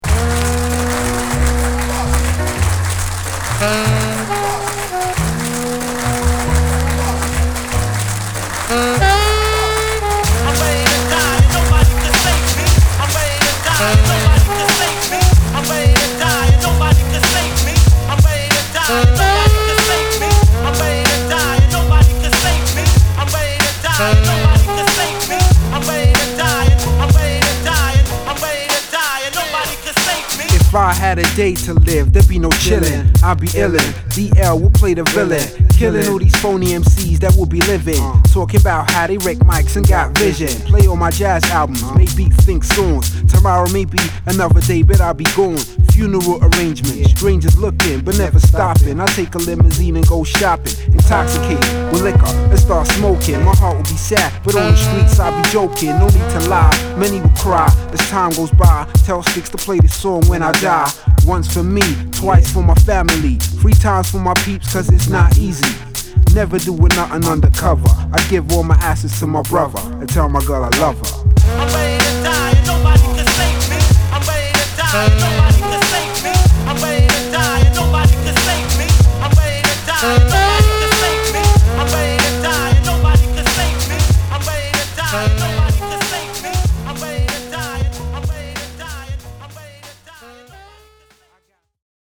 Jazzy Underground Hip Hop!!